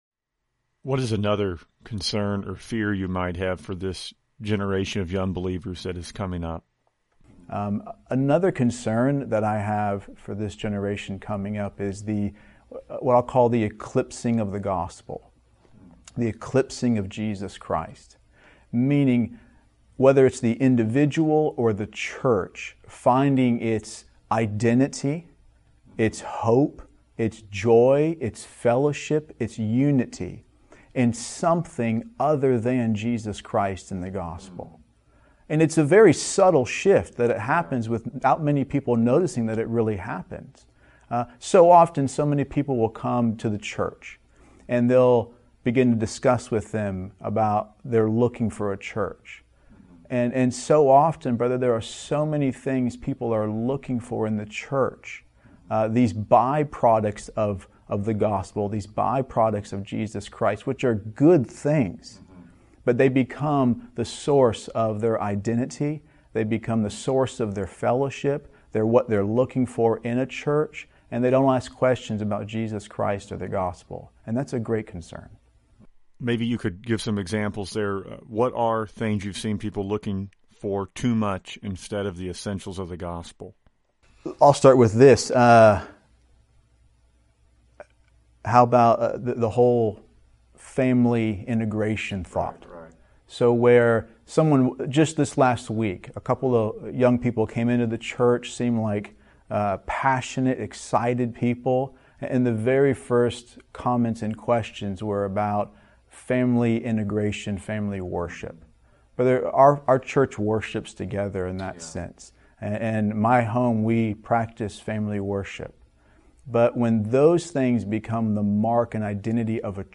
Category: Questions & Answers